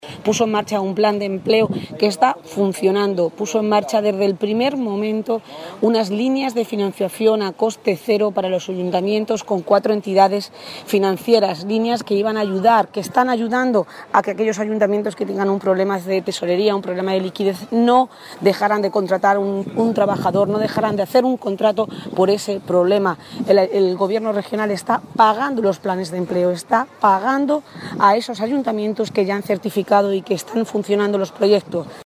La diputada socialista, Agustina García, reconoce que "es llamativo que el PP, que metió el mayor hachazo a los parados y a las políticas activas de empleo, sólo critique un plan que también ha permitido ofrecer un empleo a 1.100 jóvenes, que no tendrán que dejar C-LM"
Cortes de audio de la rueda de prensa